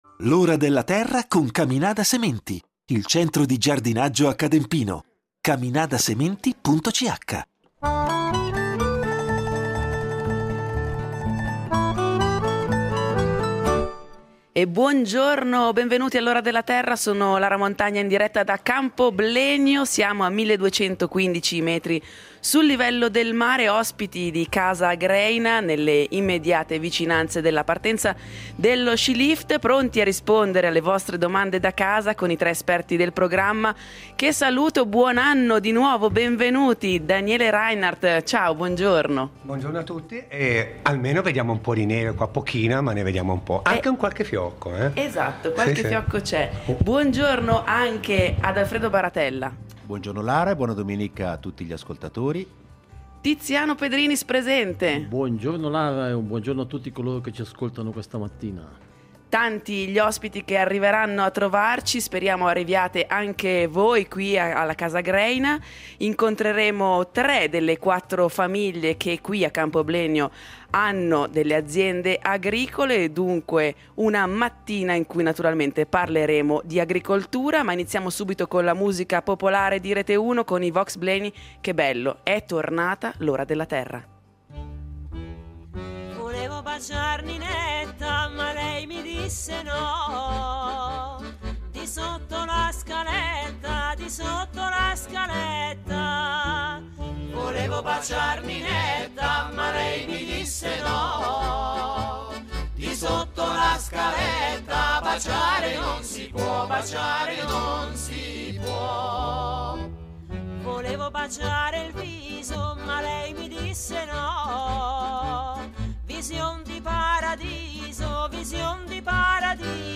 immersi nella suggestiva cornice degli impianti di sci di Campo. Sarà l’occasione per parlare con alcune famiglie contadine di agricoltura di montagna